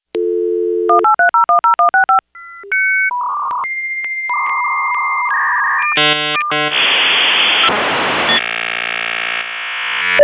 dial-up-modem-02.wav